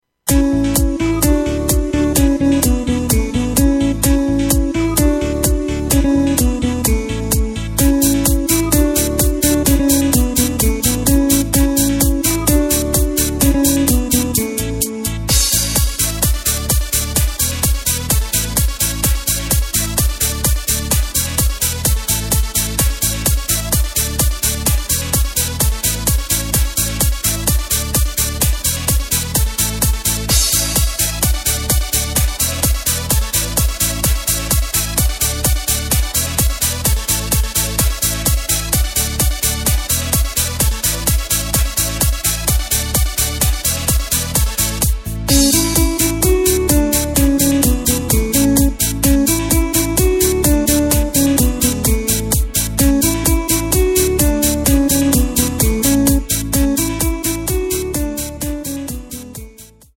Takt:          4/4
Tempo:         128.00
Tonart:            A
Hit Medley auf Rumänisch!